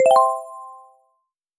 Sounds: subtler sounds ... This change sets different sounds to different volume levels, and replaces the Play sound with one that sounds different than the Add sound. 2016-03-23 20:44:40 -07:00 276 KiB Raw History
play.wav